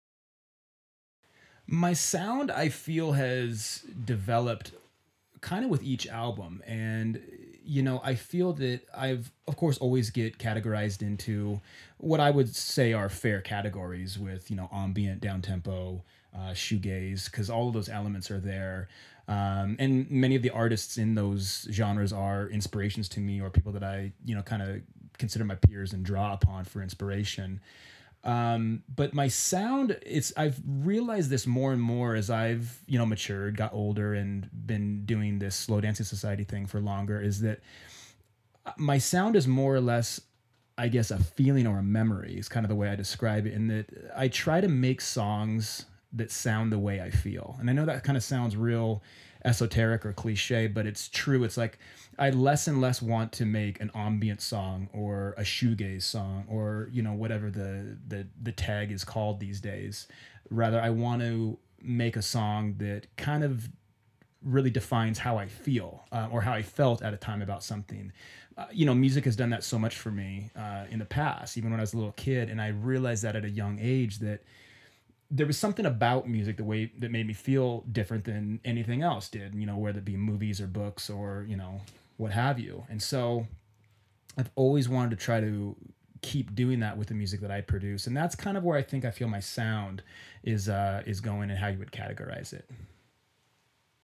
SLOW DANCING SOCIETY INTERVIEW – September 2012